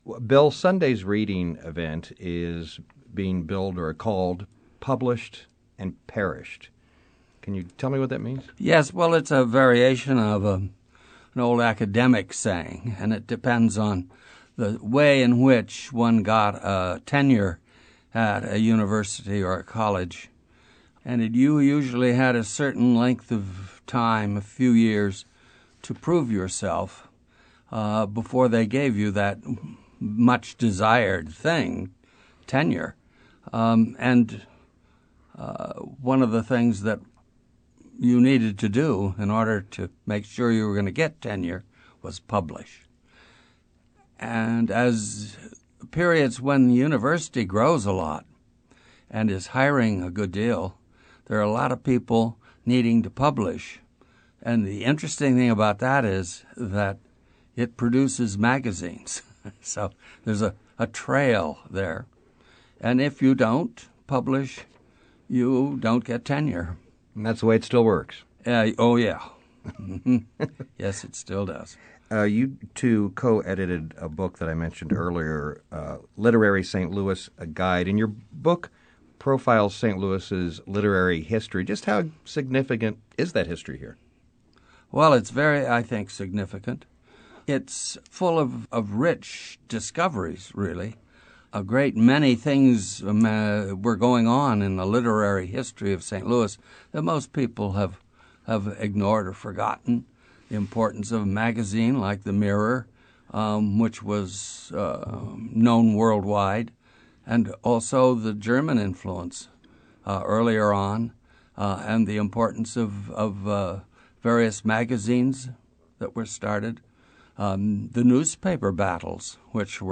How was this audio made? This segment from the Cityscape radio program promoted the "Published and Perished" benefit for the St. Louis Poetry Center.